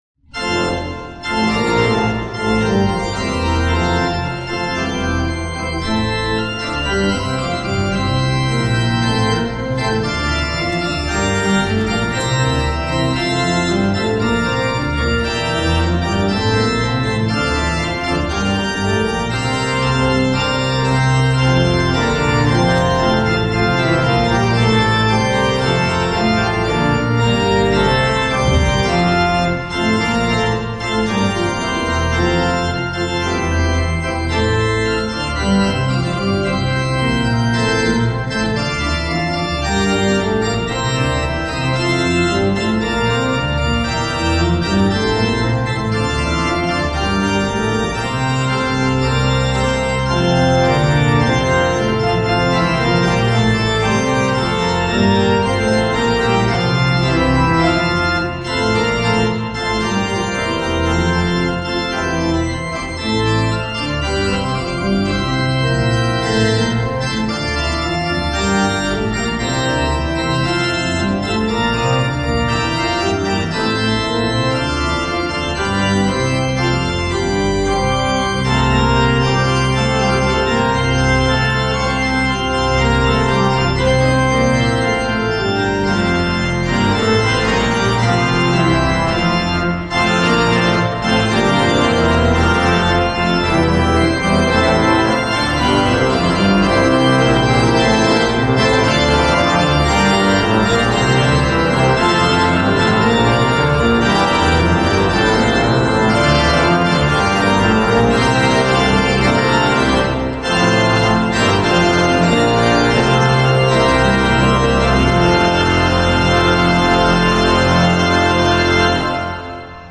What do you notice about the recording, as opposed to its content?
Hear the Bible Study from St. Paul's Lutheran Church in Des Peres, MO, from November 17, 2024. Join the pastors and people of St. Paul’s Lutheran Church in Des Peres, MO, for weekly Bible study on Sunday mornings.